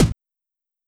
Kick (On God).wav